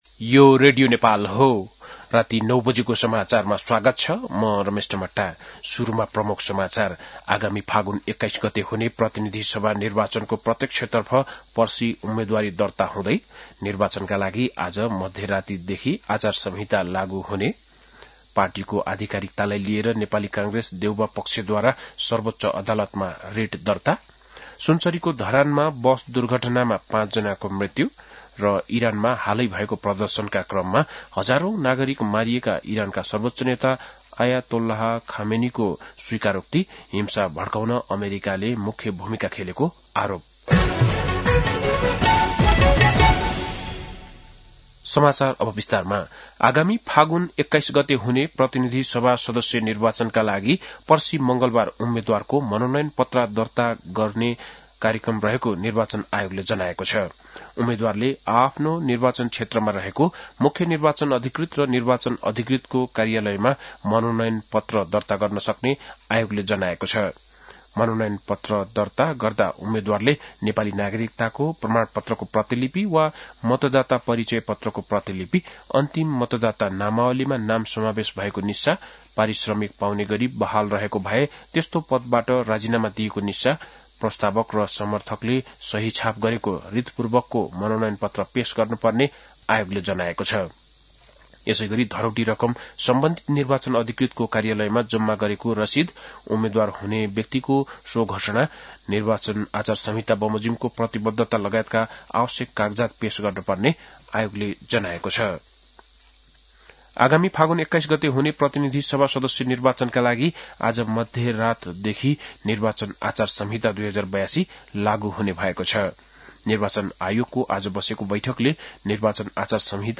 बेलुकी ९ बजेको नेपाली समाचार : ४ माघ , २०८२
9-pm-nepali-news-10-04.mp3